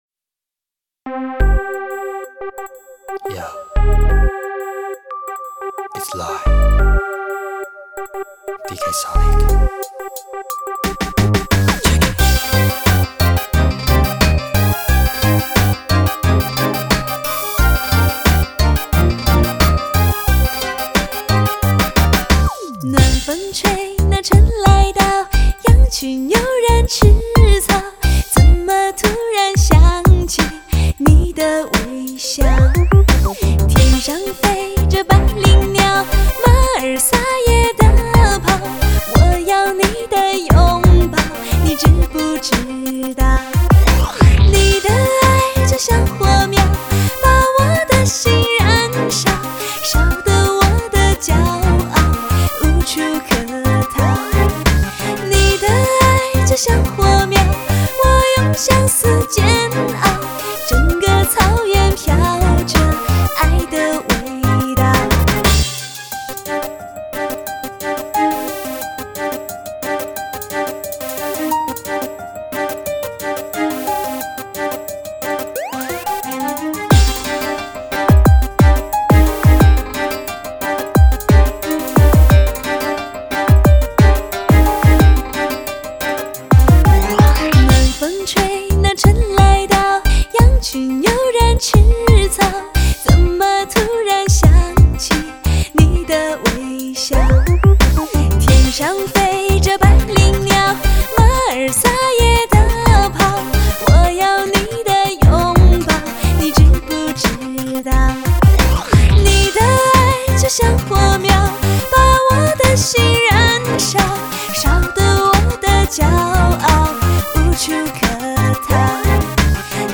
音乐类型: 汽车音乐/POP
音场超级宽广 音效超强动感 人声清晰震撼 节奏令人神共奋。